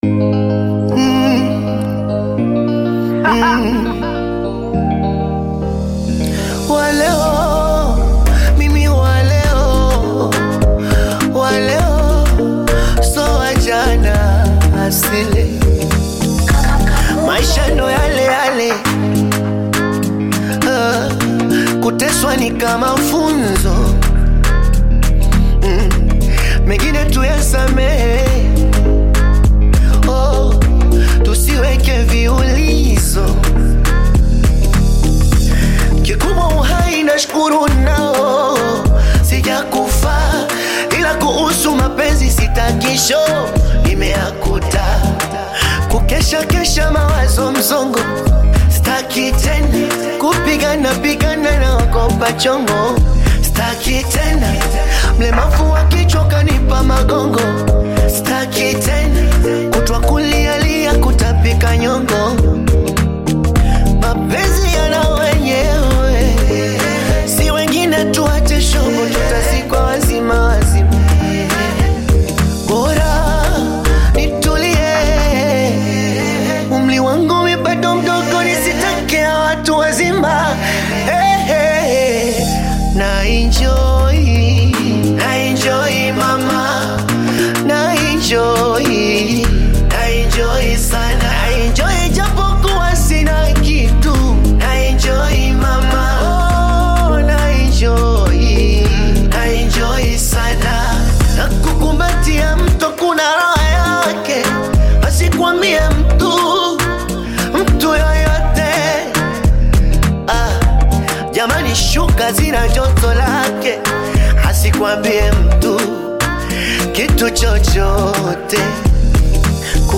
Tanzania bongo flava artist
African Music